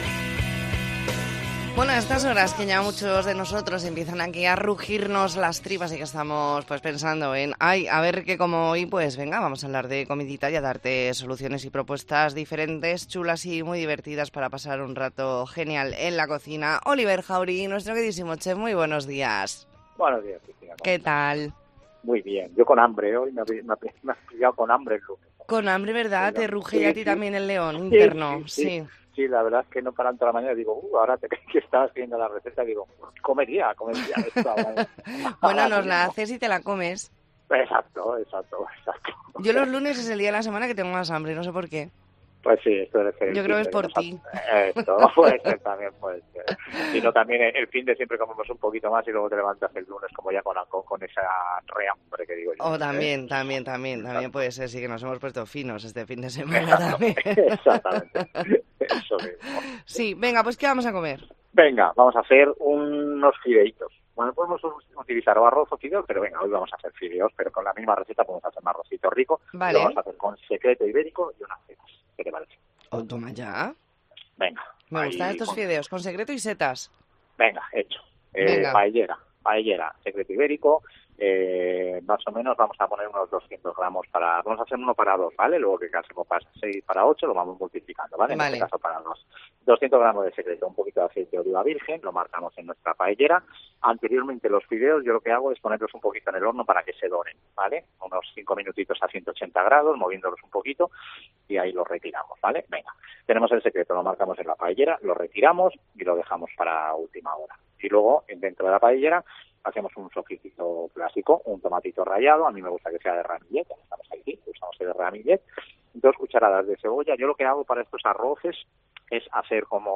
Entrevista en La Mañana en COPE Más Mallorca, lunes 20 de noviembre de 2023.